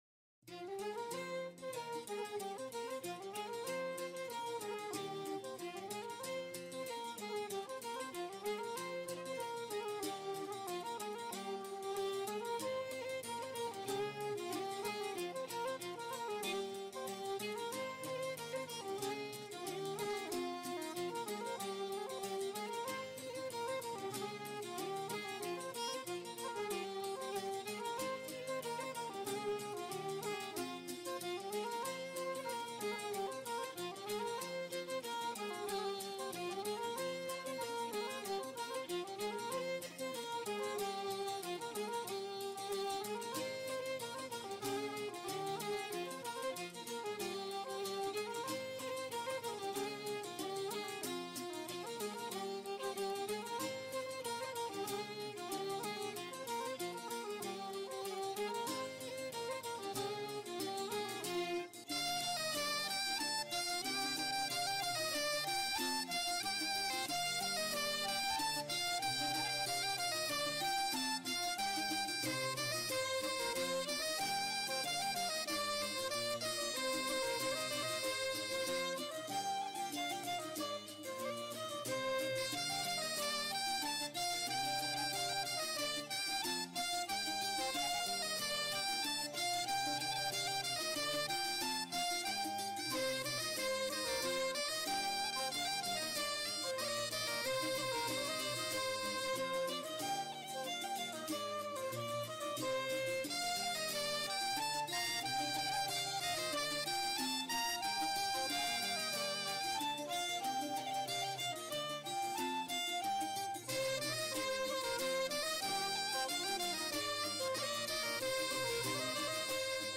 Musique bretonne Kas ha Barh - 3,27 Mo - 3 mn 37 :